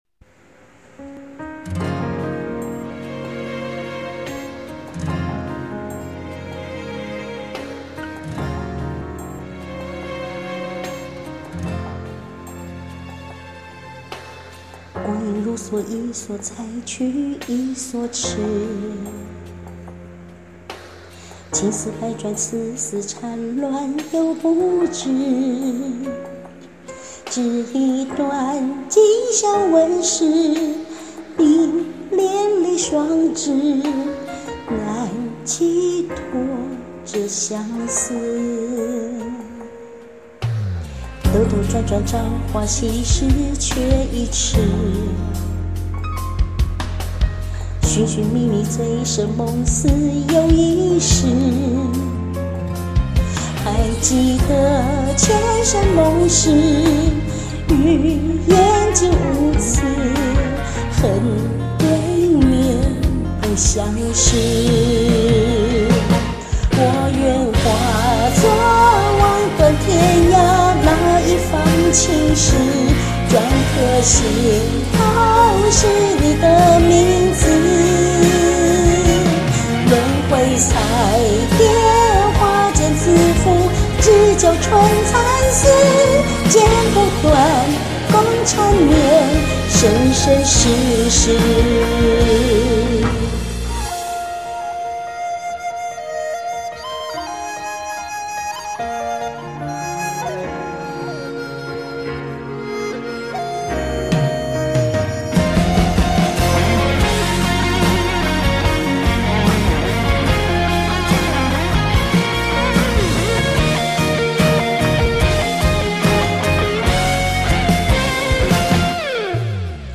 樓主大大唱得很好聽哦!!有放感情~~
都是手機錄音app錄製的喔~ ^_^